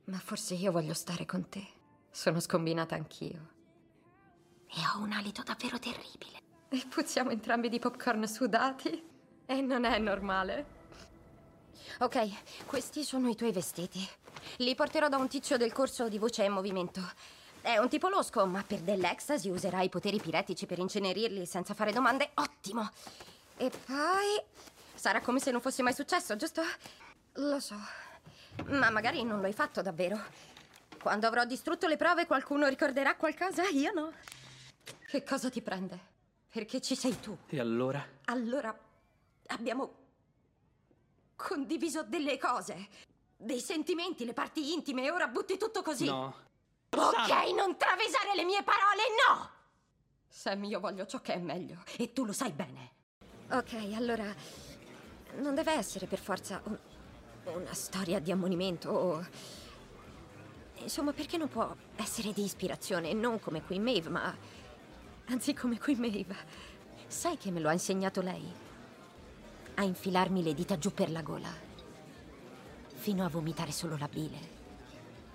nel telefilm "Gen V", in cui doppia Lizze Broadway.